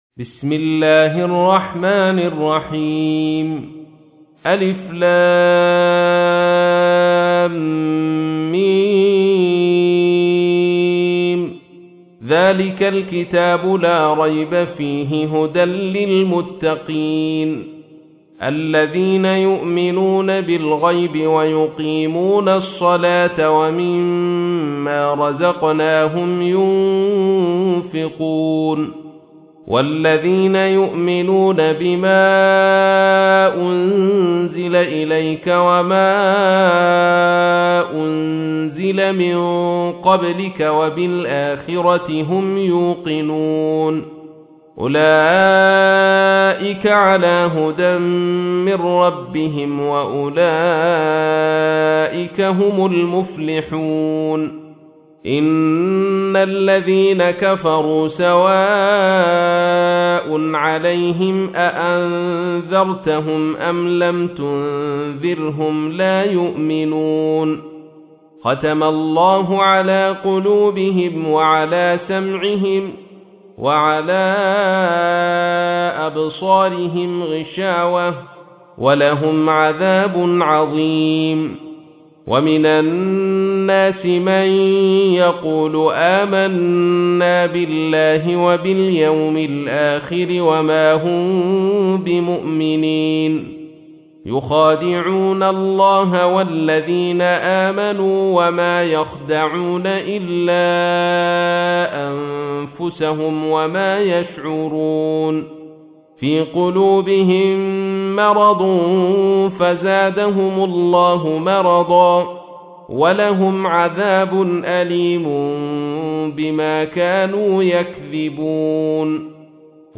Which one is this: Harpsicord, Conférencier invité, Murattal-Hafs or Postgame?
Murattal-Hafs